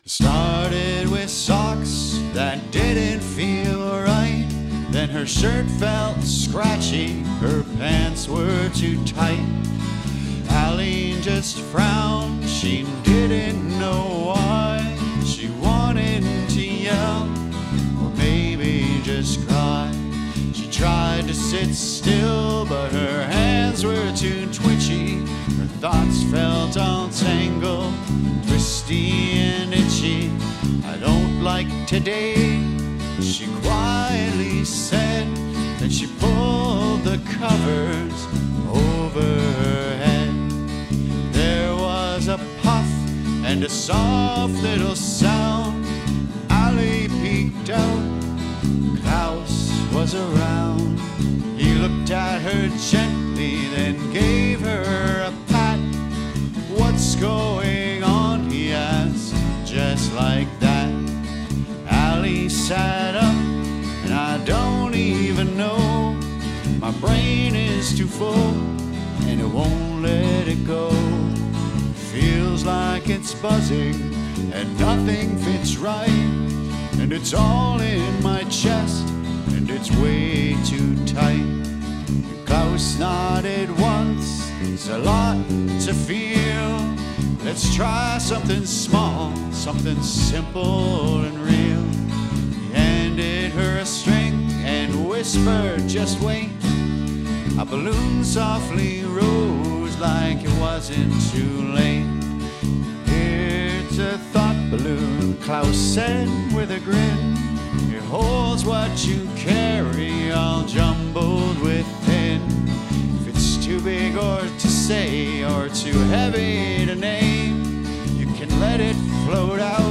Whether you’re reading along, winding down, or just letting your thoughts drift, this gentle tune brings the magic of Ali & Klaus to life in a whole new way.